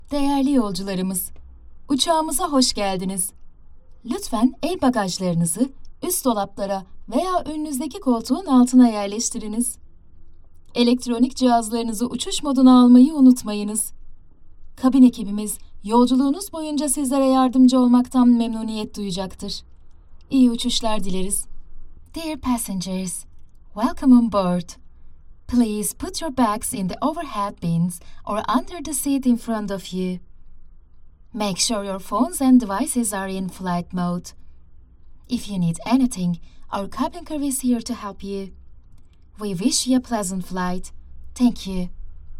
Yng Adult (18-29) | Adult (30-50)